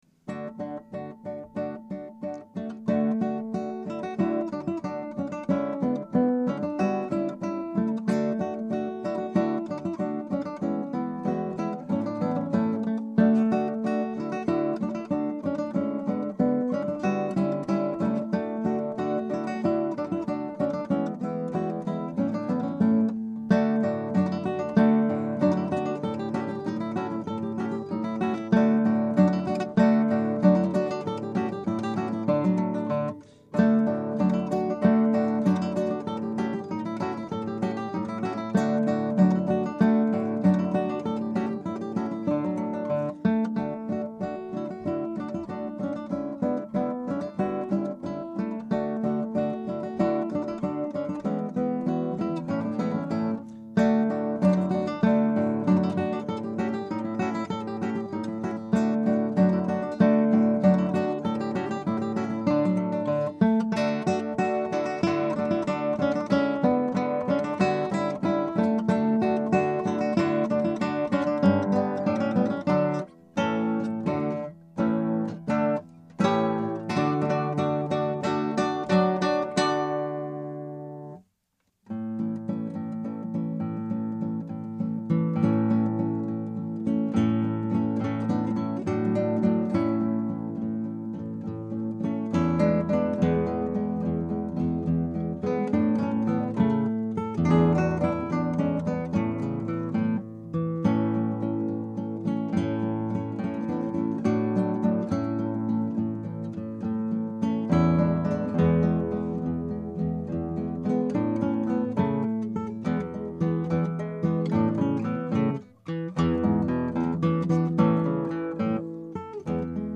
Scraps from the Operas arranged for Two Guitars
Scrap 1: Allegro non troppo.
Scrap 2 (1:22): Barcarolle. Andantino.
Scrap 3 (3:26): Marcia. Allegro mod.